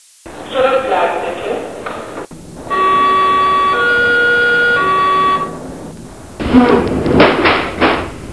Wat wel leuk is van de metro is de stem die je steeds uitnodigt om in te stappen en die je waarschuwt voor de deuren die dicht gaan.
Zurückbleiben bitte” zijn heel specifieke Berlijnse geluiden en blijven nog dagen lang nadat je terug bent van Berlijn in je hoofd naklinken.